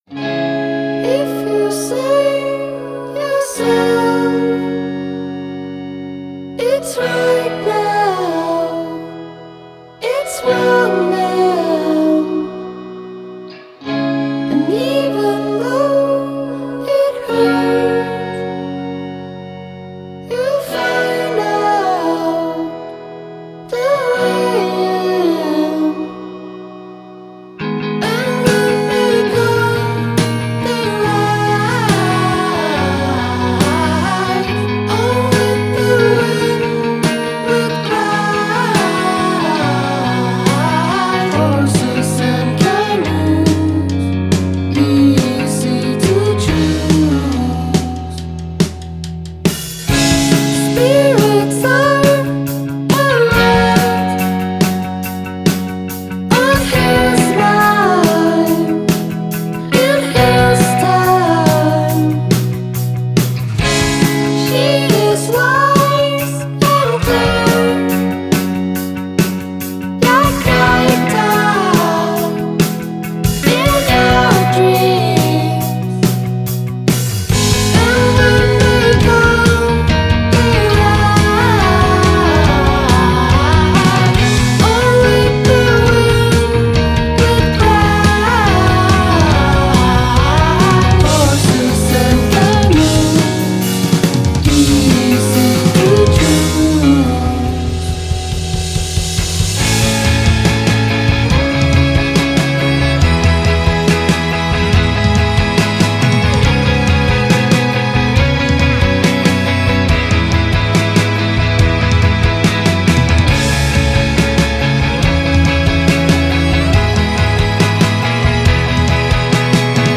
Genre: Indie/Pop/Rock
Norwegian indierockers